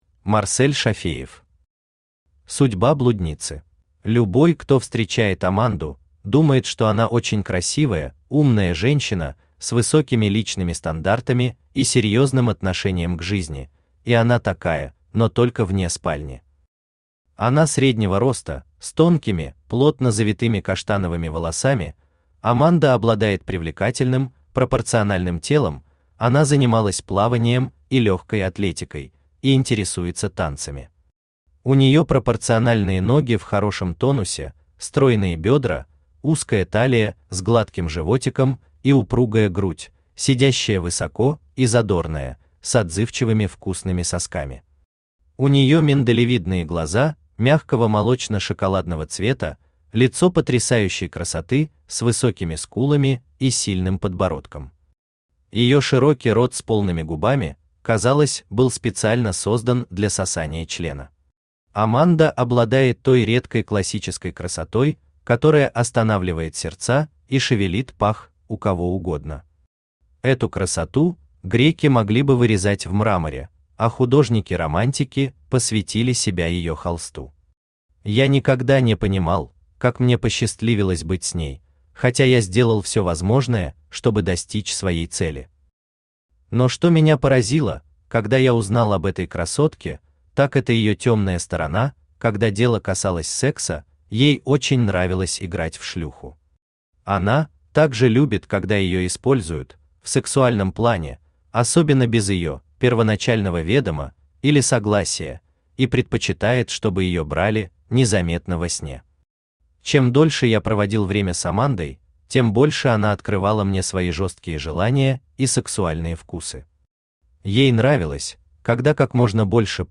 Аудиокнига Судьба блудницы | Библиотека аудиокниг
Aудиокнига Судьба блудницы Автор Марсель Зуфарович Шафеев Читает аудиокнигу Авточтец ЛитРес.